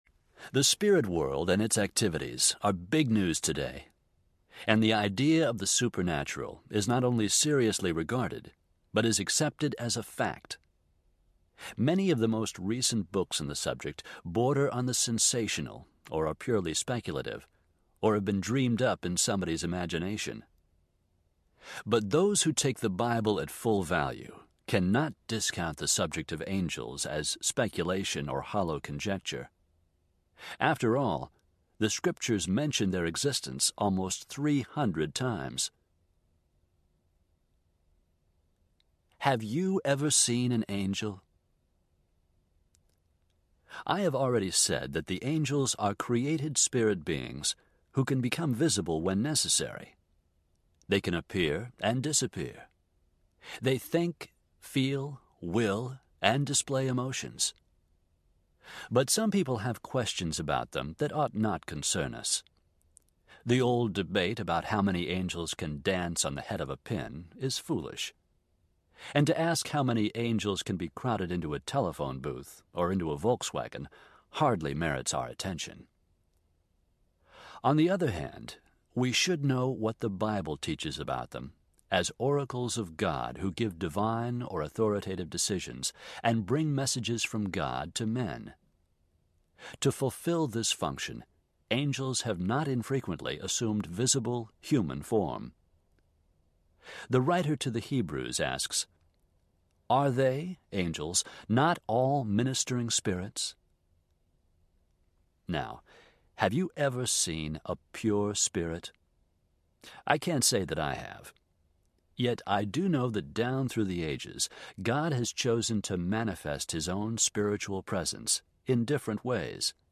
Angels Audiobook